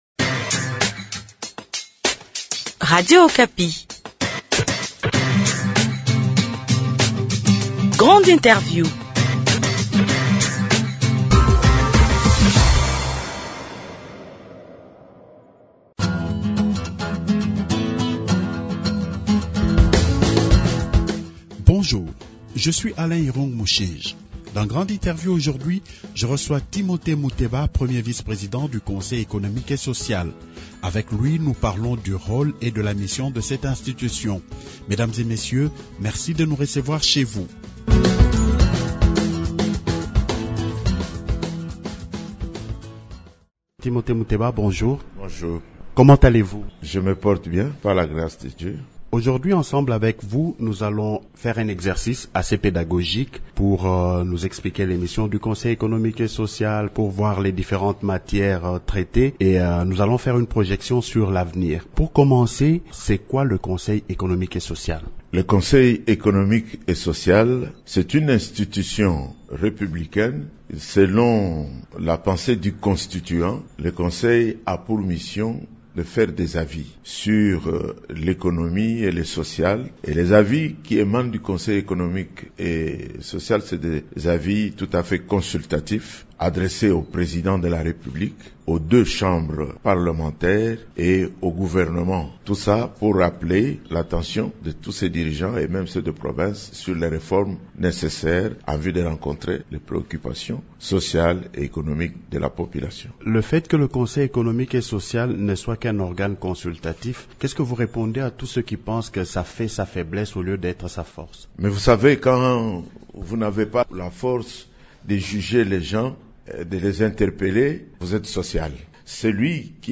Grande Interview reçoit Timothée Muteba, premier vice-président du Conseil économique et social.